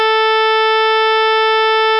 Here is an example of two sounds, produced using a computer program, which use the exact same sinusoidal functions with the exact same amplitudes, but some of them have had a phase shift applied (e.g. were delayed or advanced in time compared to the other sinusoidal functions).
As long as you keep the volume low enough so that non-linear effects (in the electronics or in your hearing) are not important, most hear them as not just similar, but identical.